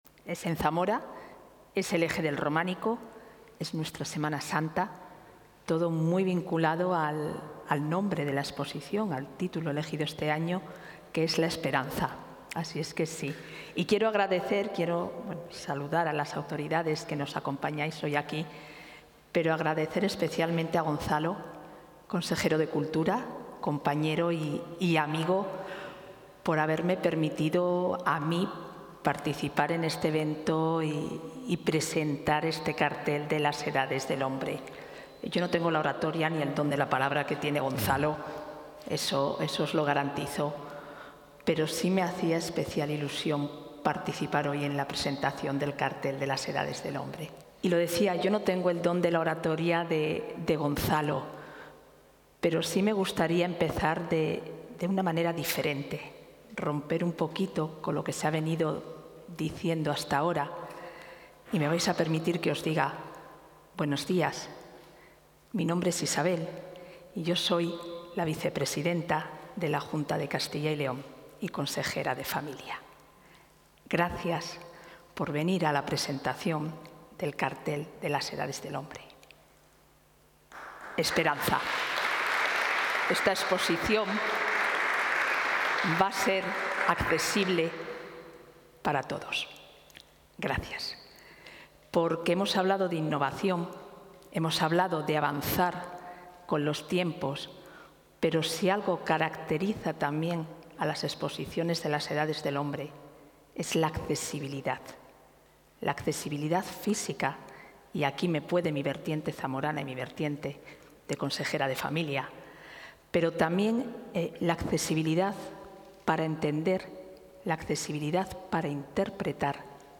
Intervención de la vicepresidenta de la Junta.